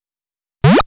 jump_ledge.wav